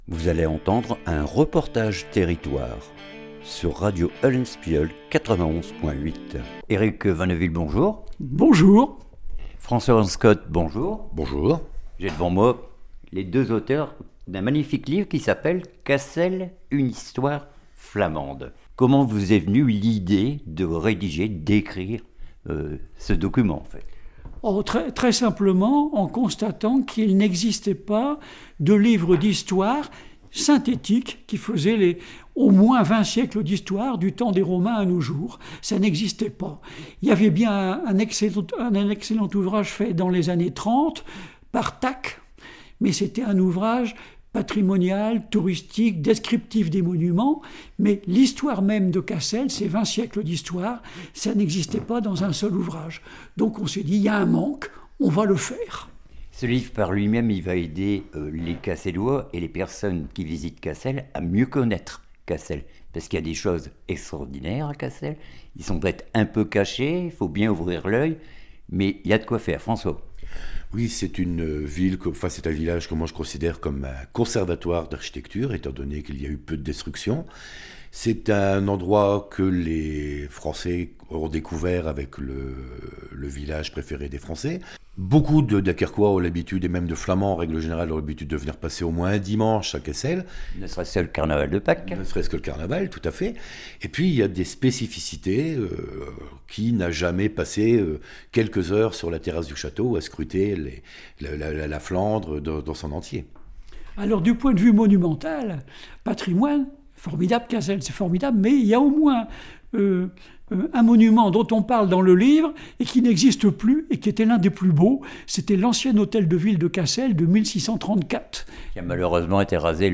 REPORTAGE TERRITOIRE CASSEL UNE HISTOIRE FLAMANDE